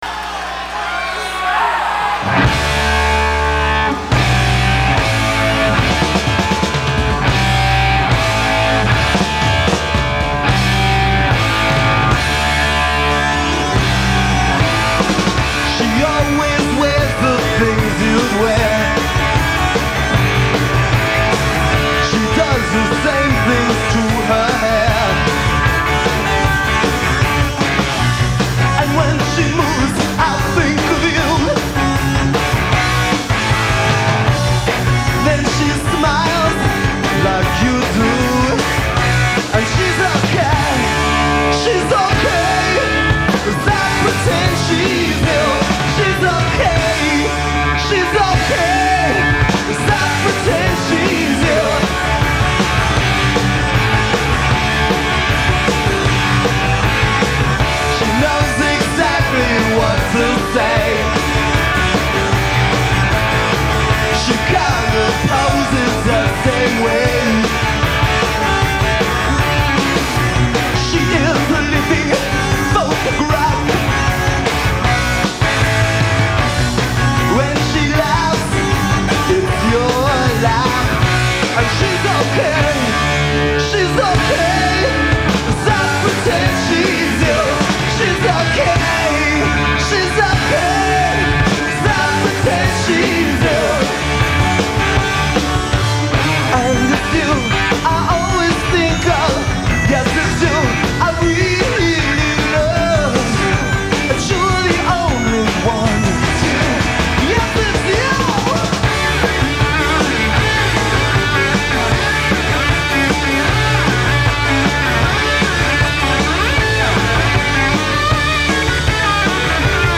lead vocals
guitar
keyboards
bass